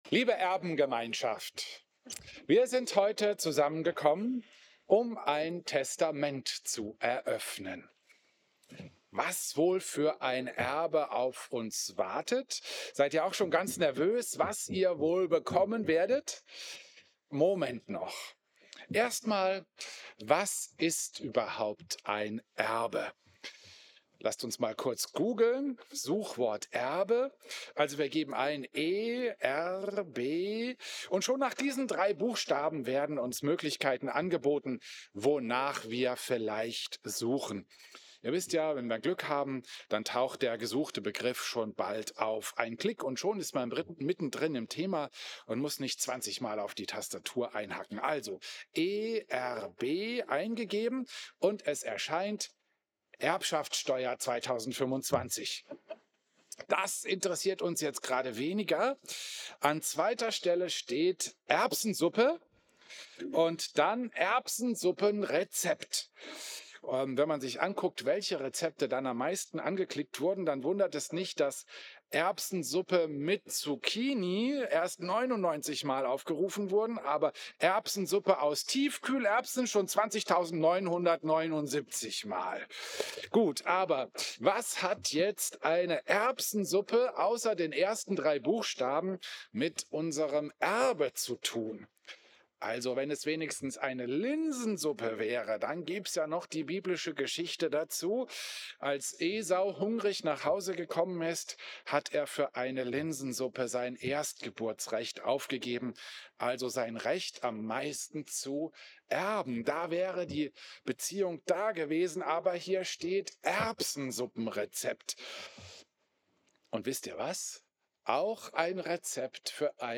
Klosterkirche Volkenroda, 17. August 2025
Predigten